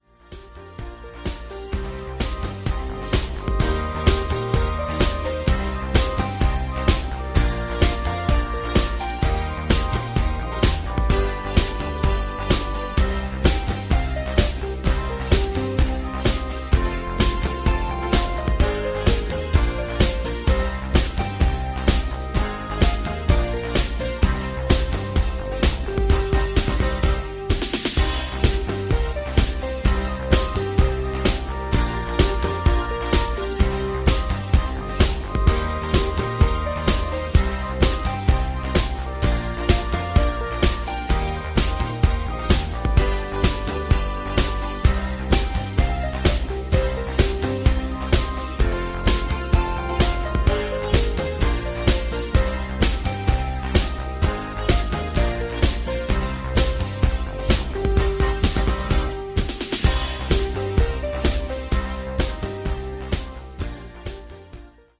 Category: Patter